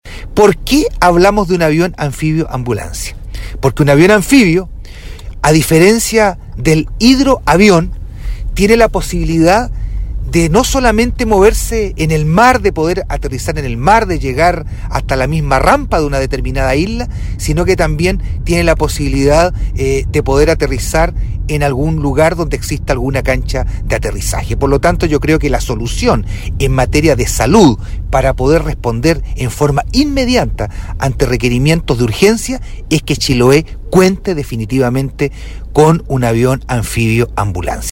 El edil explicó porque se requiere el avión anfibio con características de ambulancia.
CUÑA-2-JUAN-EDUARDO-VERA.mp3